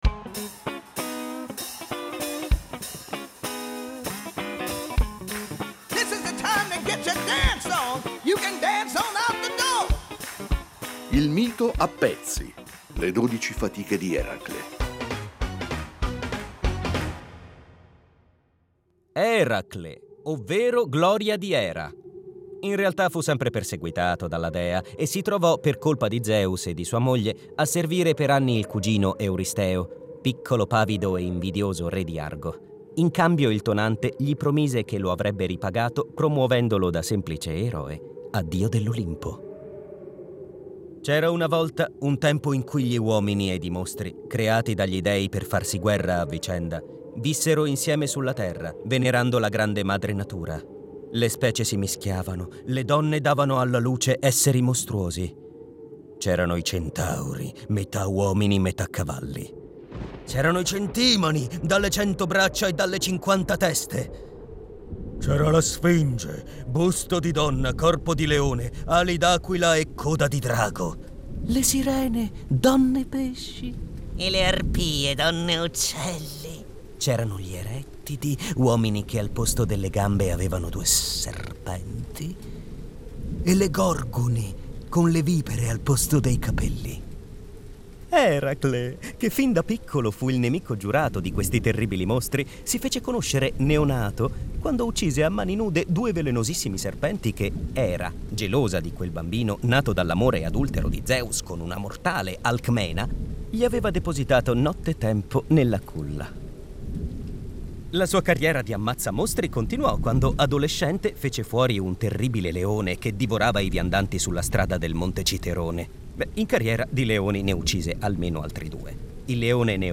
con tono leggero, ma preciso